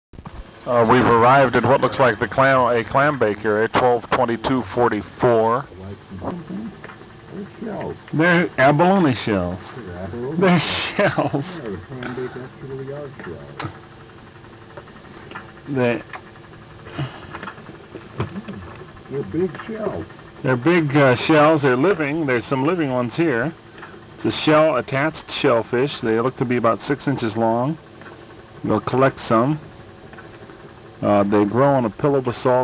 From inside Alvin